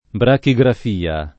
brachigrafia [ braki g raf & a ] s. f.